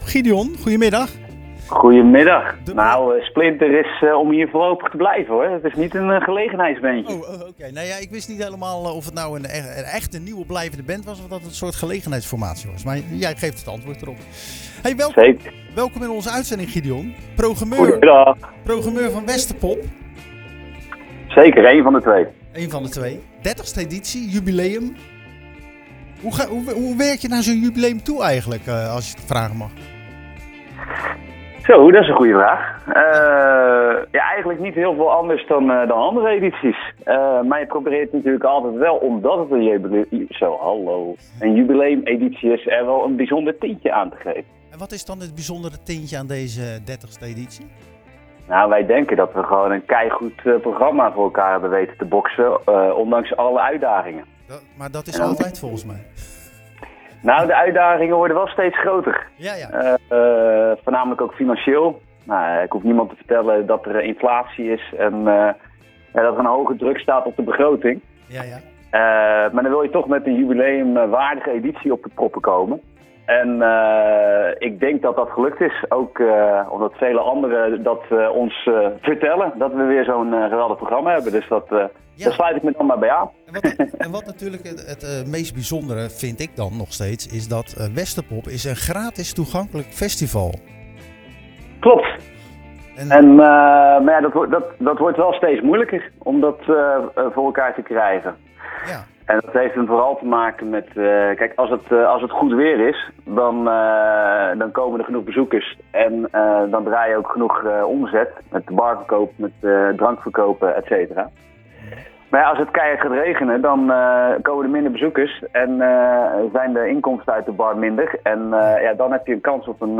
Tijdens het programma Zwaardvis schakelde we 'live' over naar Westerpop in Delft.